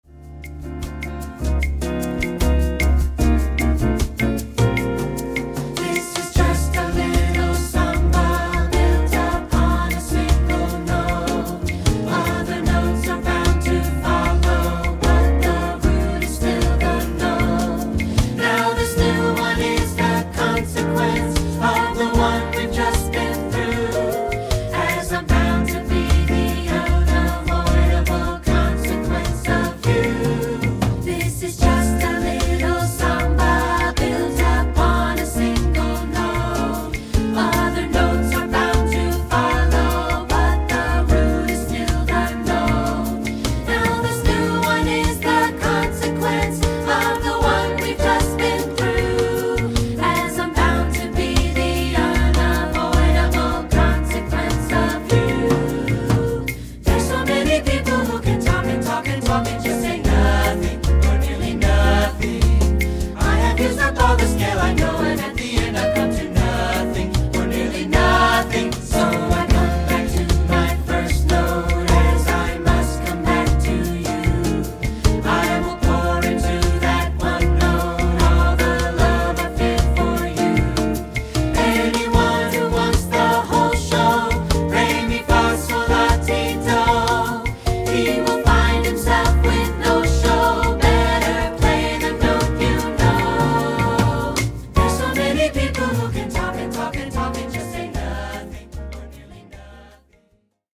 The smooth sounds of Brazilian jazz